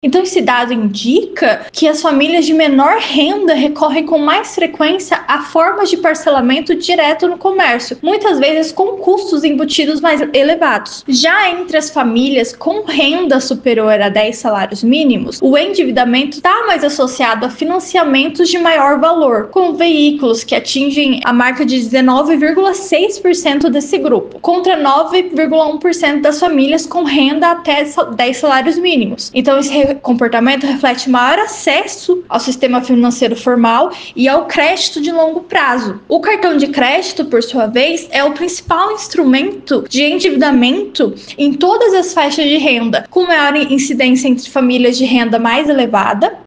Em entrevista ao Agora 104 a economista deu mais informações.